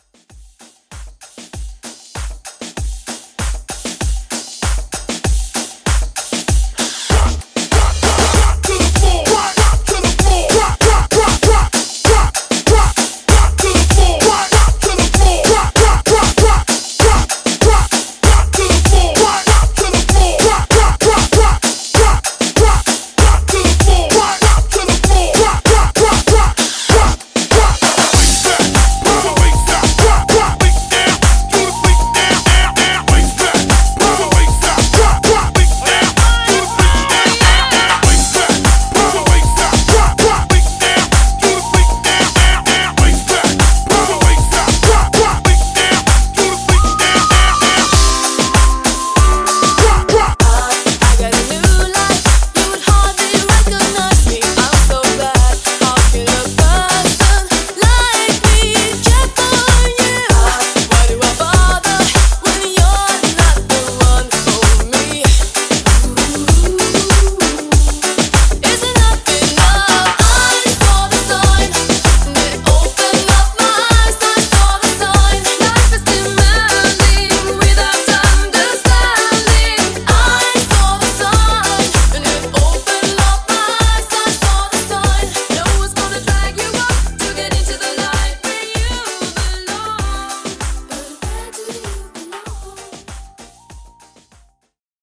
Party Breaks Moombah)Date Added